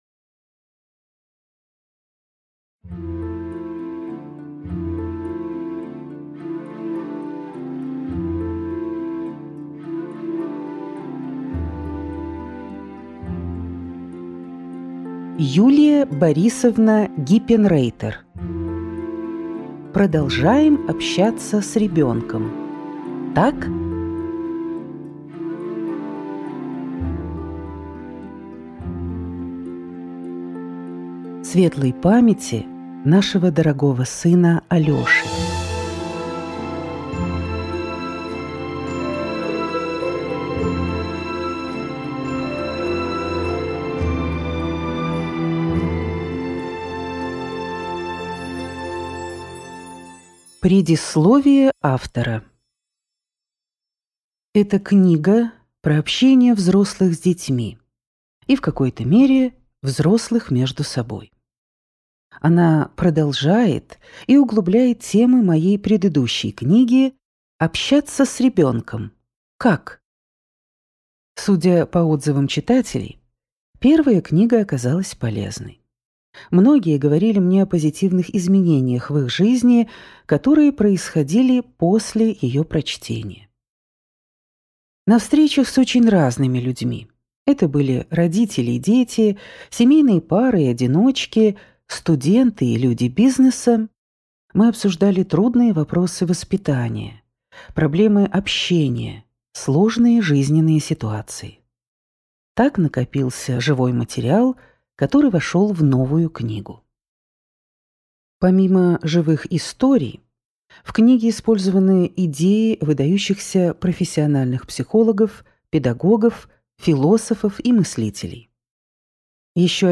Аудиокнига Продолжаем общаться с ребенком. Так?